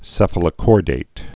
(sĕfə-lə-kôrdāt)